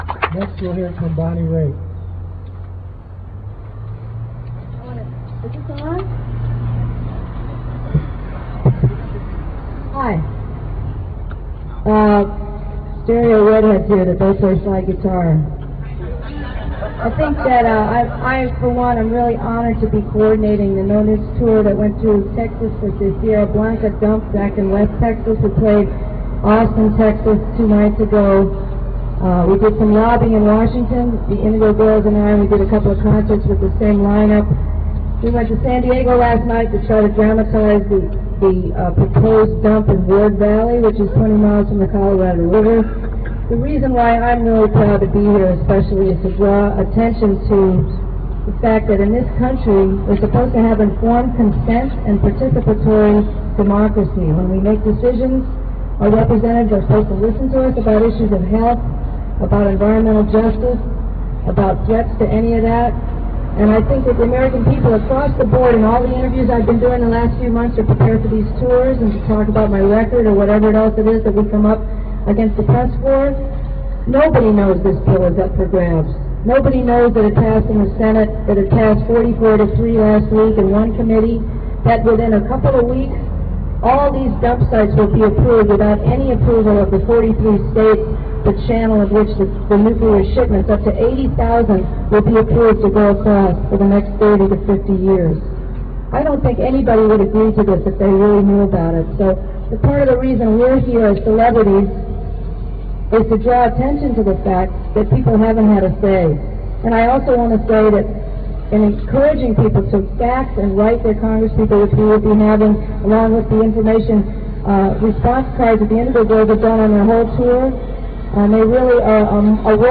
08. press conference - bonnie raitt (3:31)